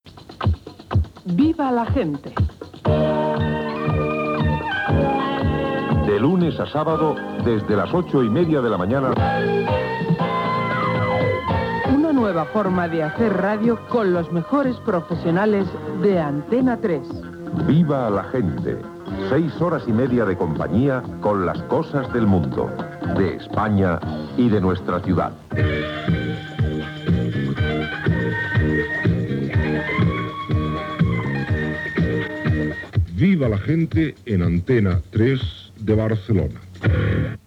Careta del programa
Entreteniment